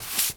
sweeping_broom_leaves_stones_07.wav